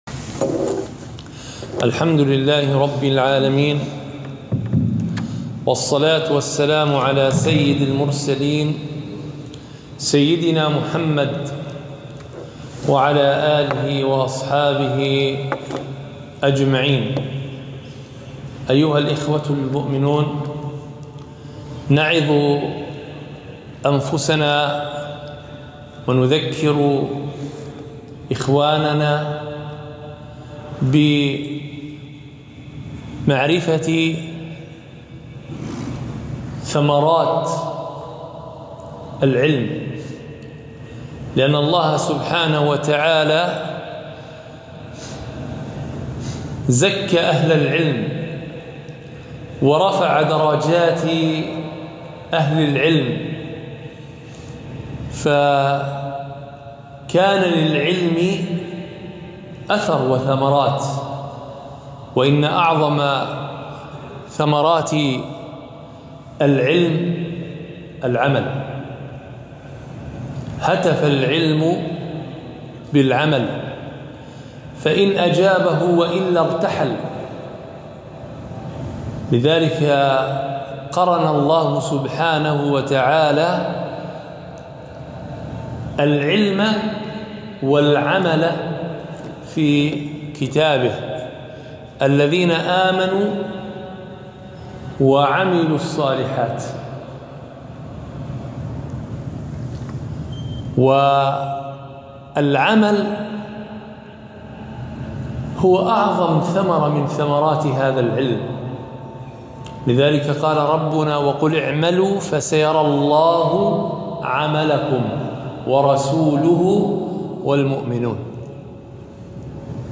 كلمة - ثمرات العلم والإيمان